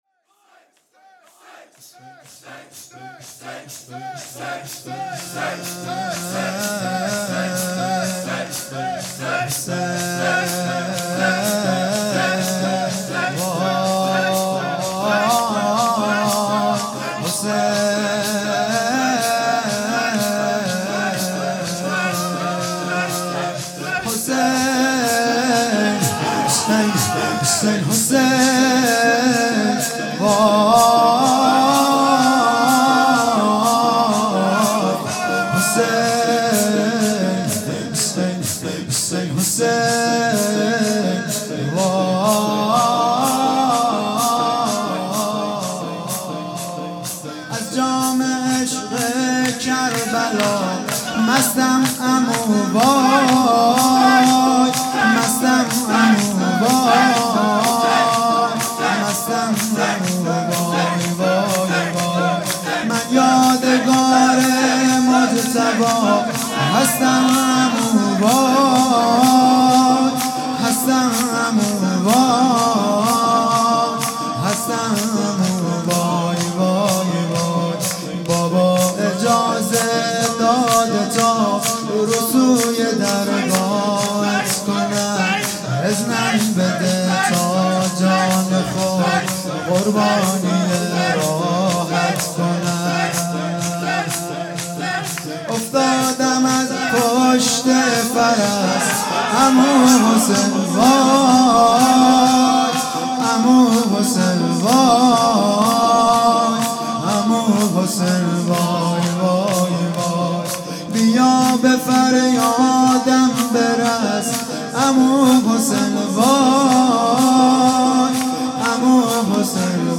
نوا
شب ششم محرم